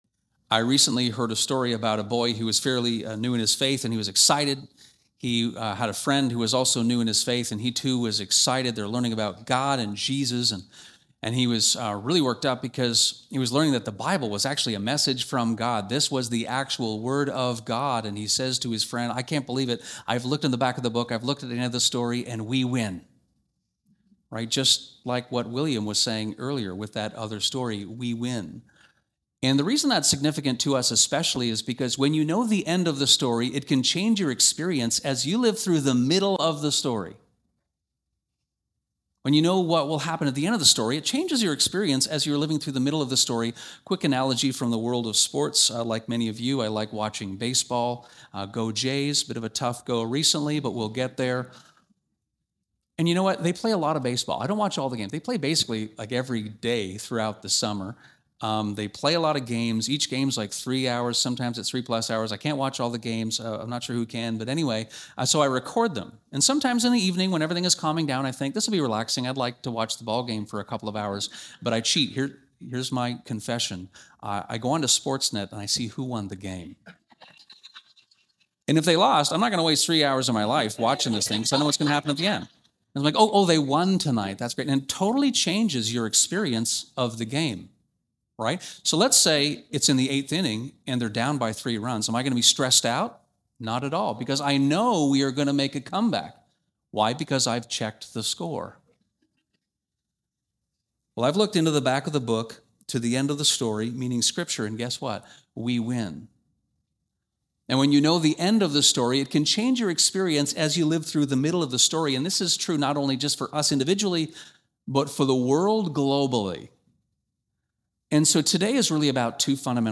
Sermons | Westminster